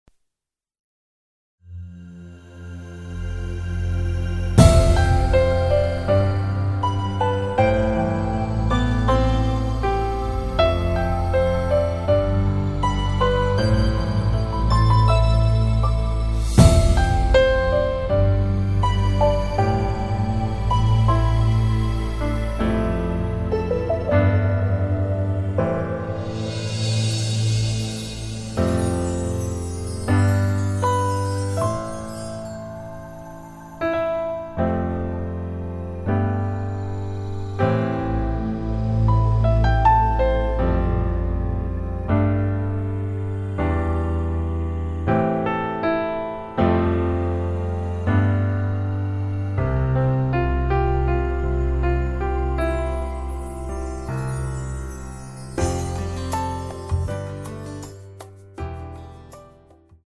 歌曲调式：升C调